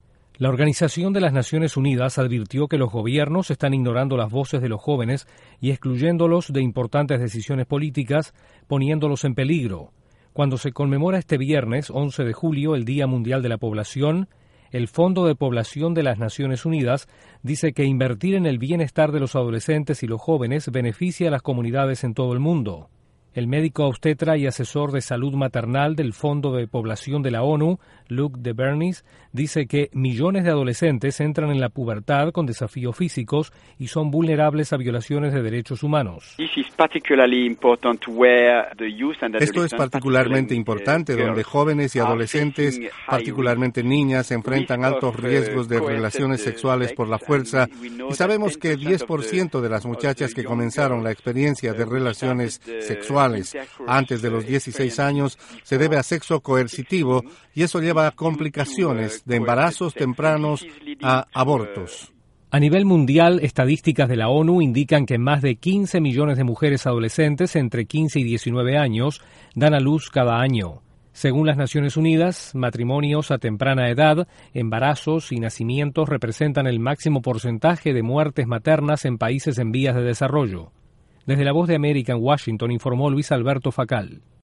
Al conmemorarse hoy el Día Mundial de la Población, la ONU pide a los gobiernos no excluir a los jóvenes. Desde la Voz de América en Washington informa